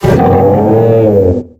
Cri d'Aflamanoir dans Pokémon X et Y.